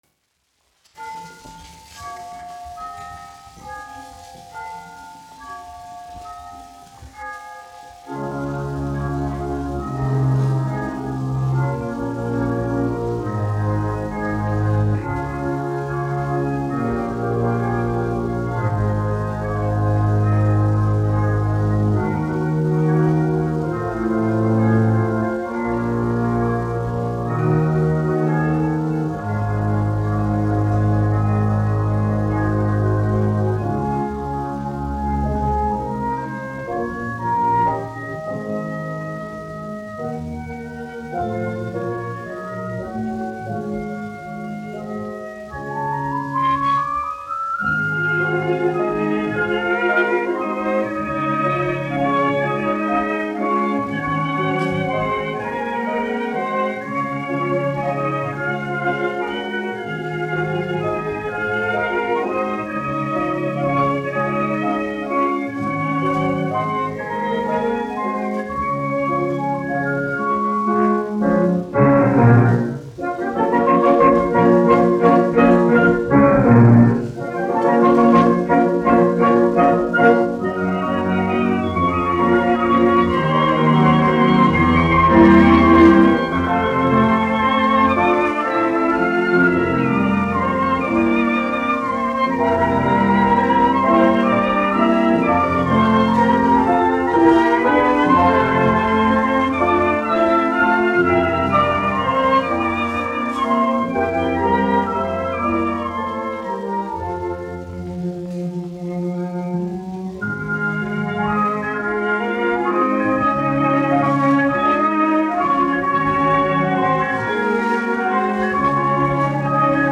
1 skpl. : analogs, 78 apgr/min, mono ; 25 cm
Orķestra mūzika, aranžējumi
Latvijas vēsturiskie šellaka skaņuplašu ieraksti (Kolekcija)